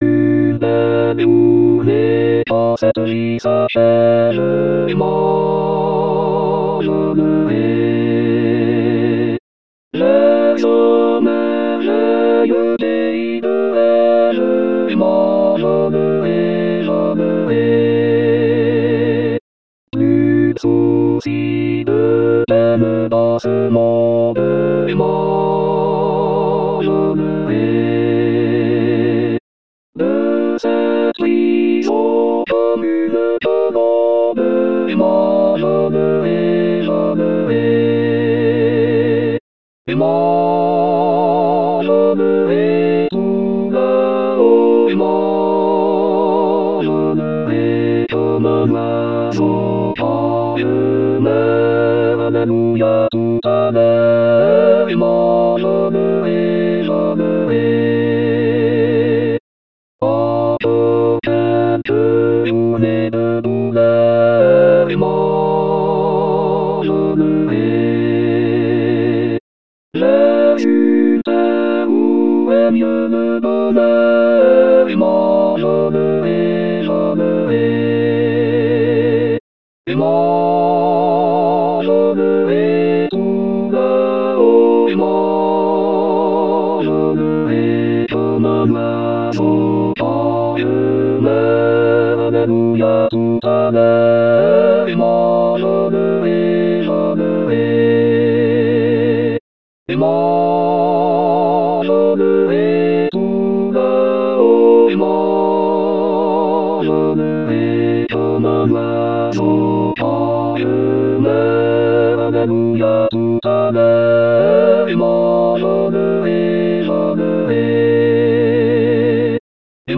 Cet hymne